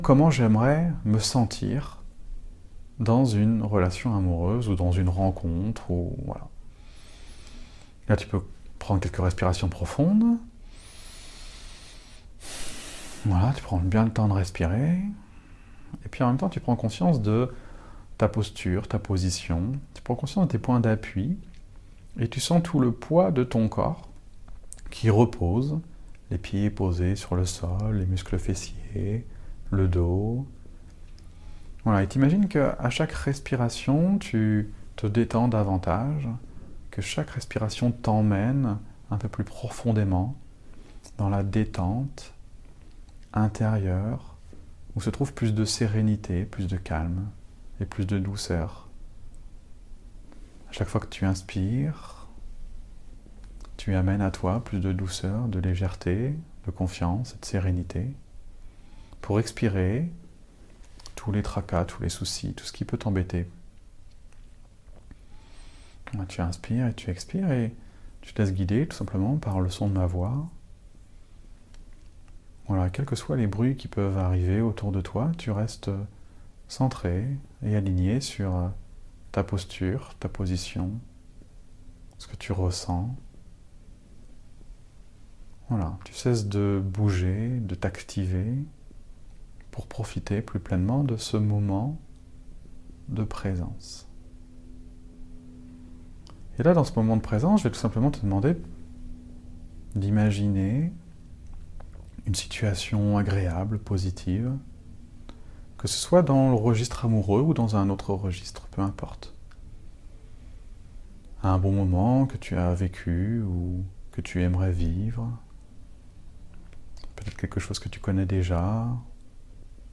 QR39-Meditation-Comment-avancer-sans-peur-de-souffrir.mp3